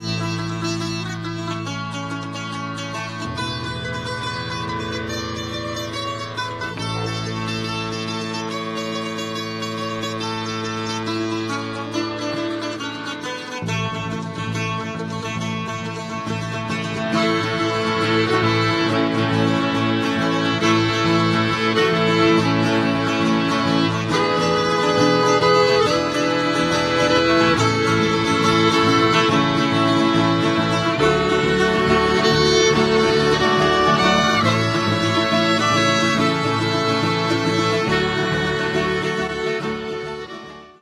skrzypce
bouzuki, mandolina, gitara akustyczna
gitara basowa, gitara akustyczna